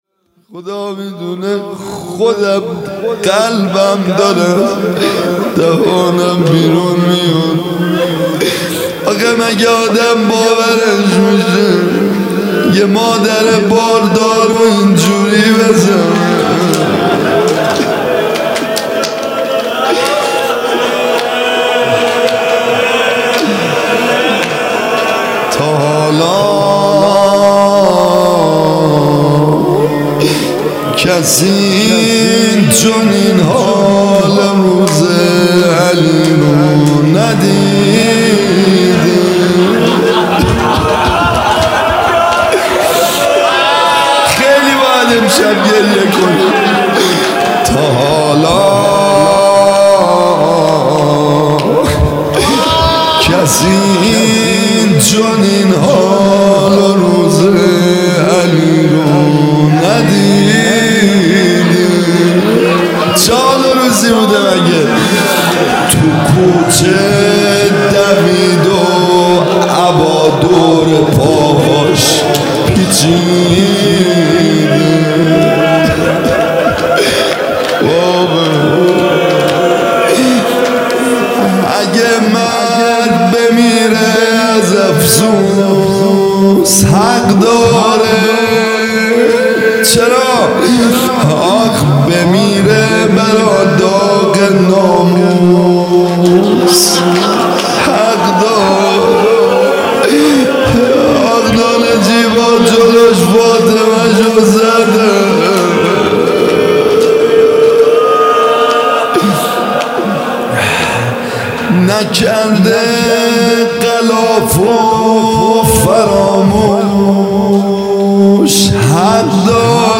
music-icon روضه